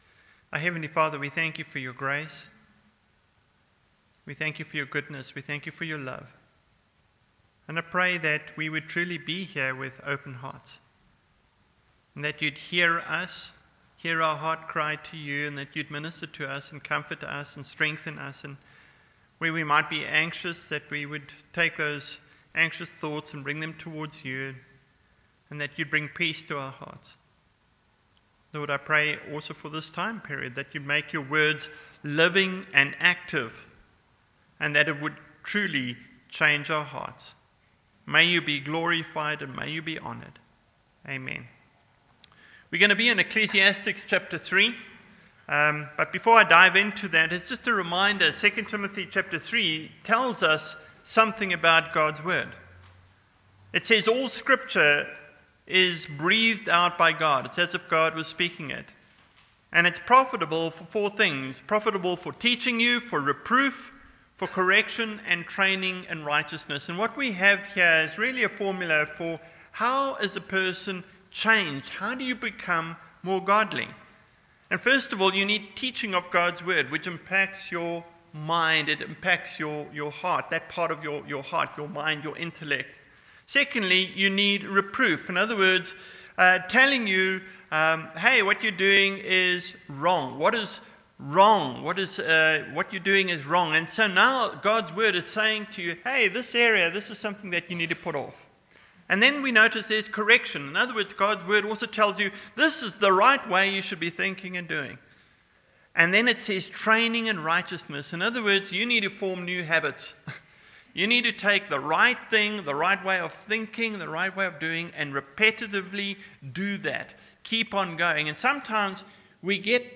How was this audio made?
Passage: Ecclesiastes 3: 1-14 Service Type: Morning Service